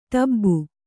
♪ tab'b'u